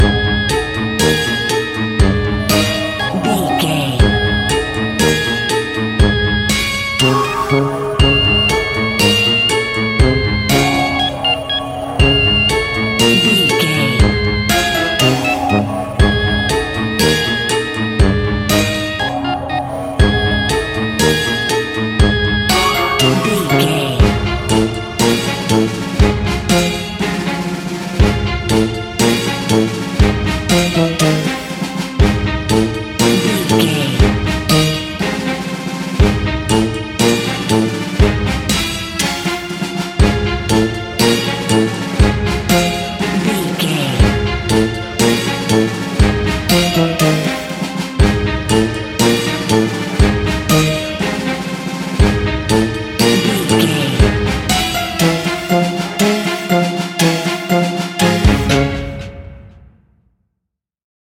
Aeolian/Minor
C#
Slow
ominous
eerie
percussion
brass
synthesiser
strings
creepy
horror music